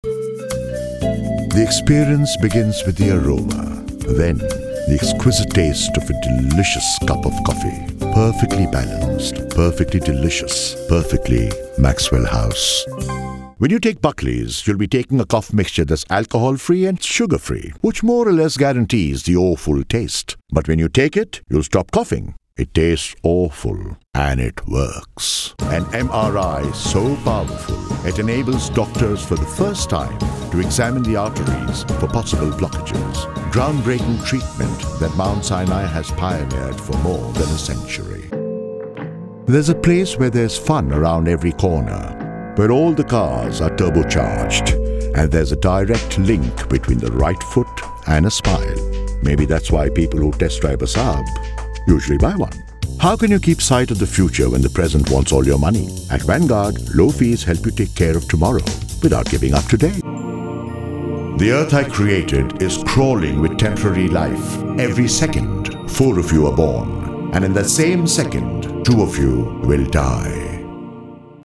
Warm, deep, sexy, up-market.
Sprechprobe: Werbung (Muttersprache):